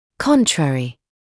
[‘kɔntrərɪ][‘kɔntrərɪ]обратный, противоположный